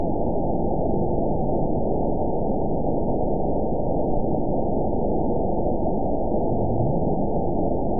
event 921652 date 12/14/24 time 21:52:21 GMT (4 months, 3 weeks ago) score 8.60 location TSS-AB04 detected by nrw target species NRW annotations +NRW Spectrogram: Frequency (kHz) vs. Time (s) audio not available .wav